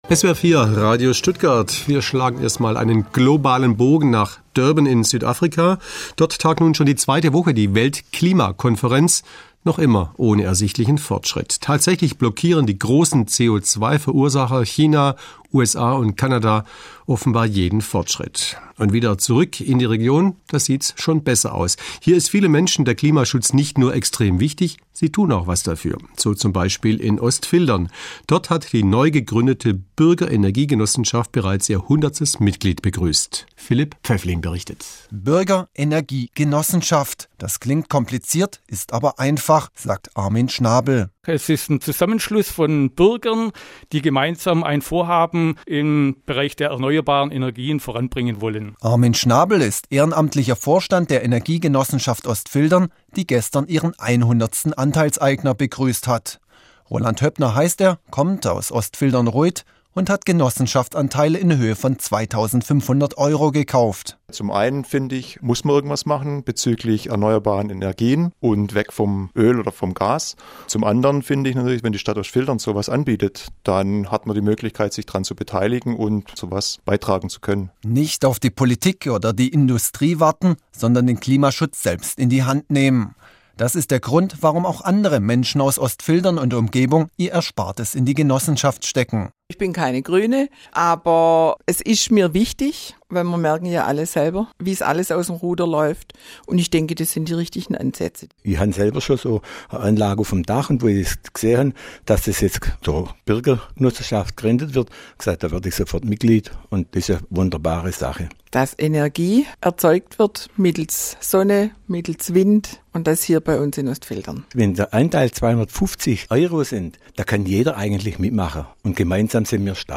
Mitschnitt der Sendung vom 7. Dezember 2011 veröffentlichen.